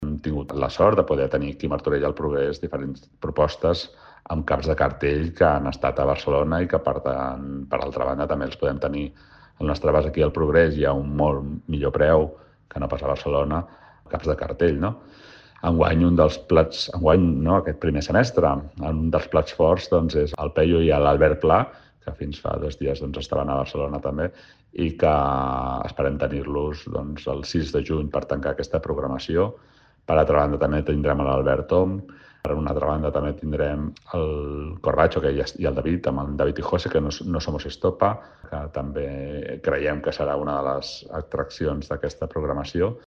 Sergi Corral, regidor de Cultura de l'Ajuntament de Martorell